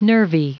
Prononciation du mot nervy en anglais (fichier audio)
Prononciation du mot : nervy